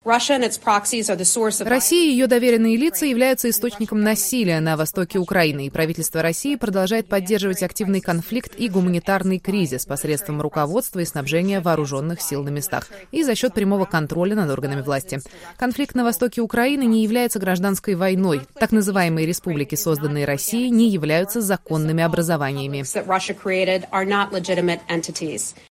Пресс-секретарь Госдепартамента США Хизер Нойерт, выступая во вторник (19 декабря) на брифинге в Вашингтоне, призвала Россию положить конец боевым действиям на востоке Украины и согласиться на размещение миссии миротворцев ООН.